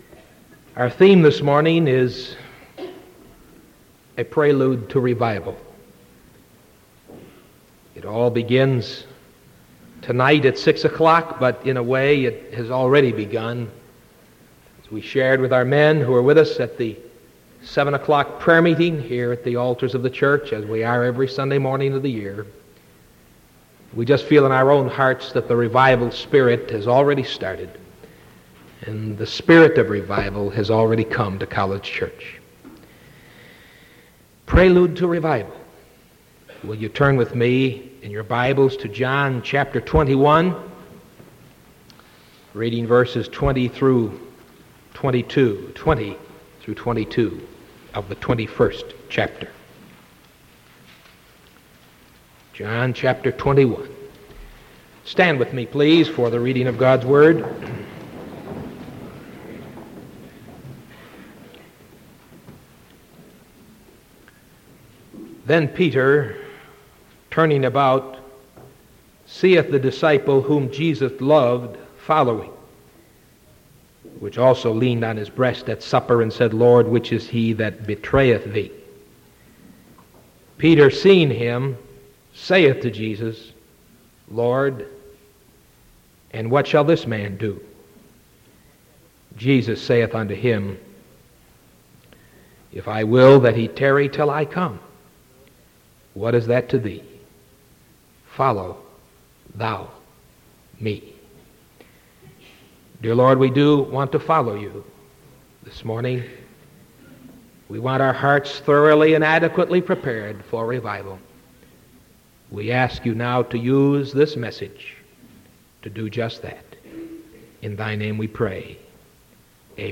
Sermon from October 26th 1975 AM